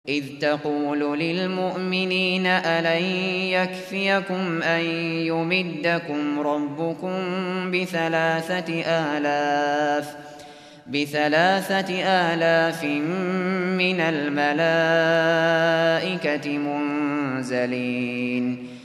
Hafız Abu Bakr al Shatri sesinden 3/ÂLİ İMRÂN-124 dinle!